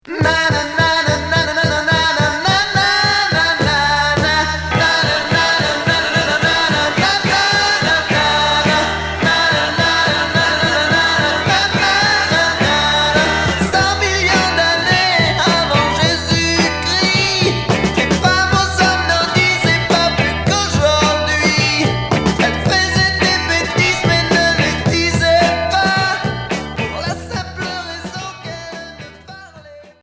Garage mod